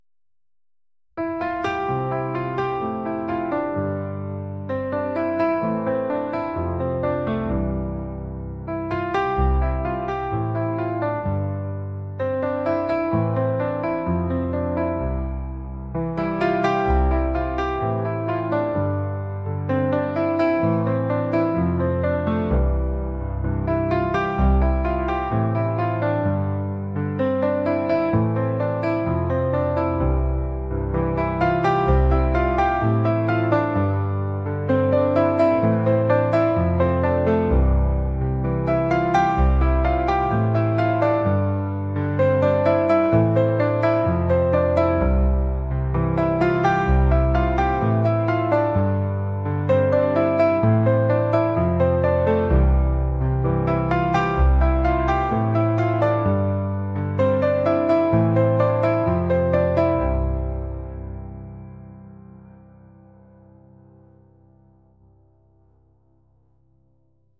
pop | cinematic | soulful